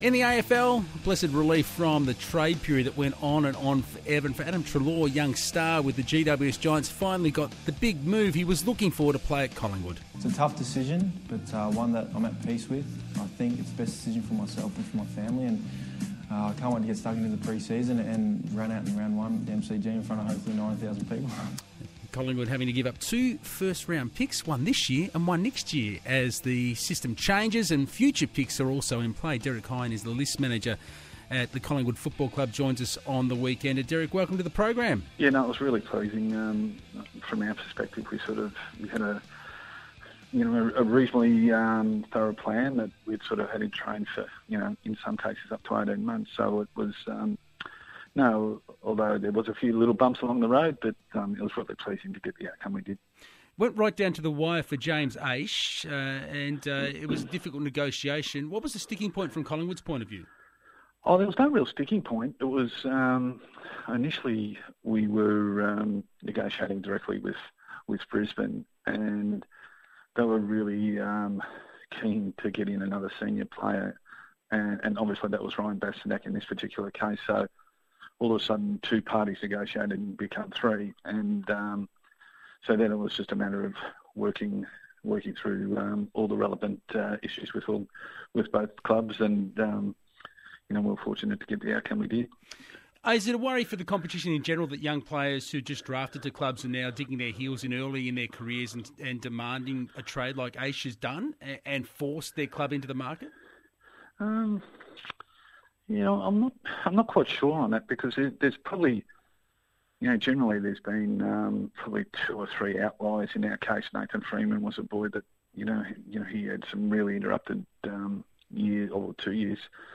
speak to the Grandstand Weekender on ABC Radio.